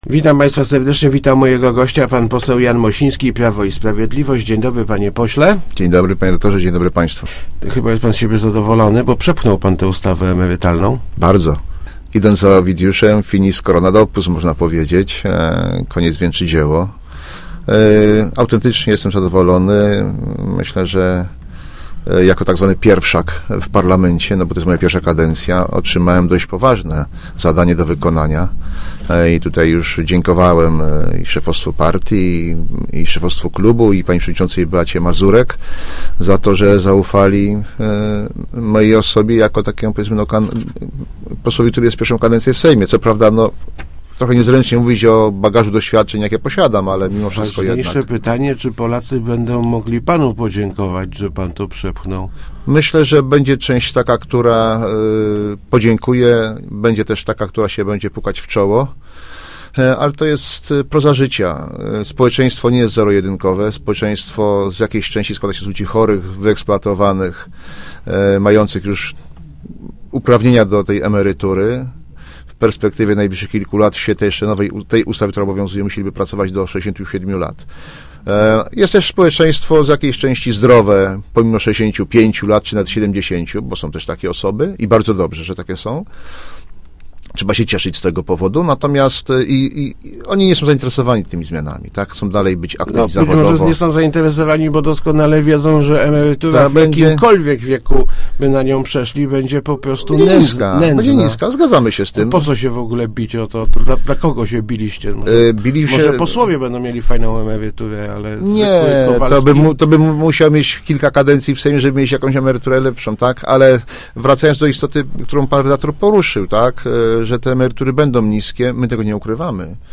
Obni�enie wieku emerytalnego uwa�am za swój osobisty sukces - mówi� w Rozmowach Elki pose� Jan Mosi�ski (PiS), który sta� na czele komisji prowadz�cej prace nad projektem ustawy.